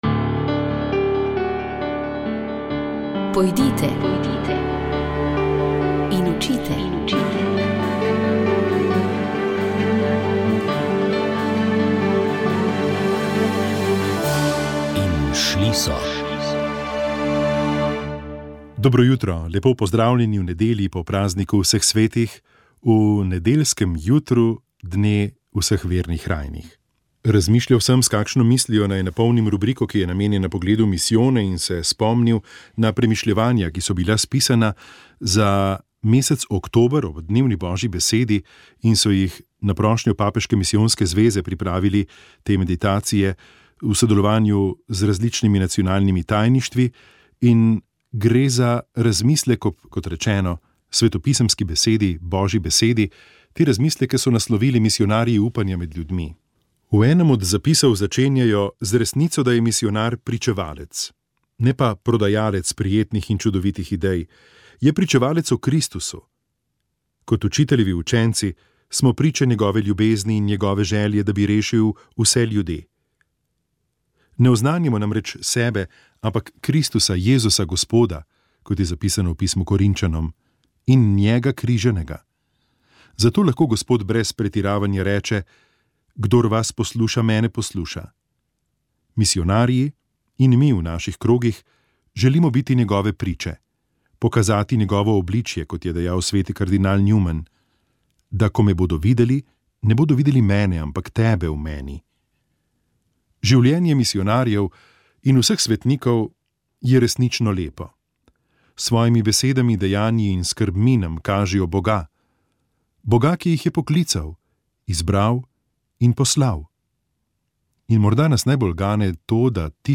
V oddaji Utrip Cerkve v Sloveniji smo objavili oba dela pastirskega pisma slovenskih škofov za letošnji postni čas. Med drugim ste slišali tudi povabilo k preventivni postni akciji 40 dni brez alkohola ter nagovor mariborskega nadškofa Alojzija Cvikla pri zadnji sveti maši v kapeli Srca Jezusovega v Mariboru.